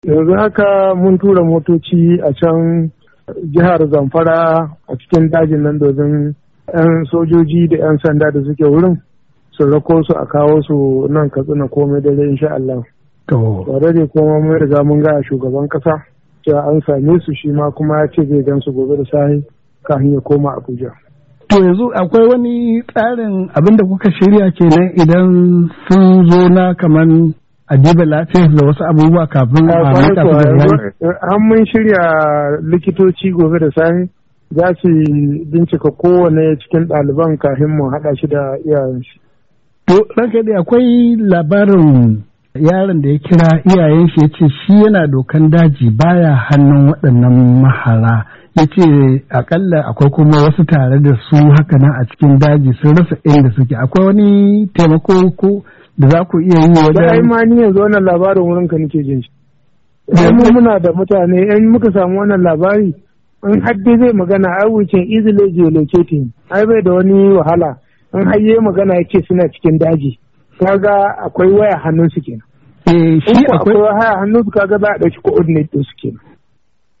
Hira da gwamna Aminu Bello Masari kan ceto daliban Kankara:1:30"